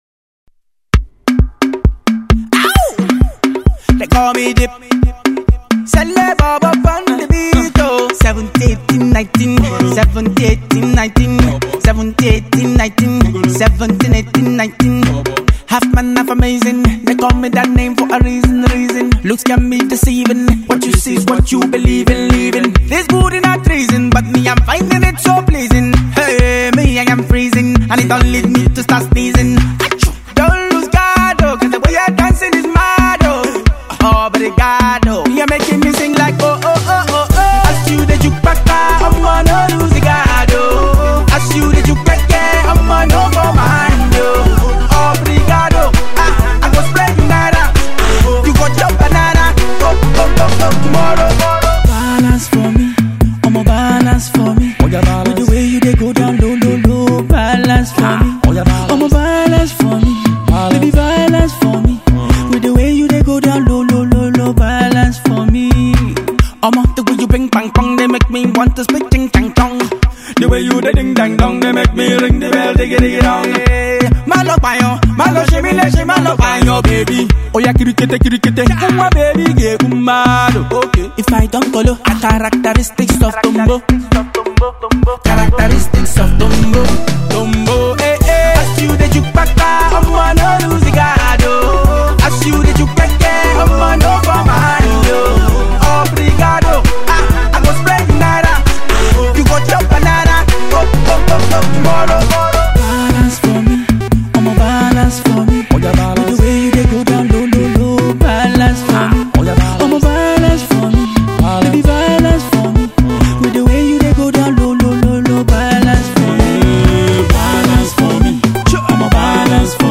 A tune for the dancefloor…